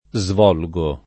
vai all'elenco alfabetico delle voci ingrandisci il carattere 100% rimpicciolisci il carattere stampa invia tramite posta elettronica codividi su Facebook svolgere [ @ v 0 l J ere ] v.; svolgo [ @ v 0 l g o ], -gi — coniug. come volgere — cfr. svolvere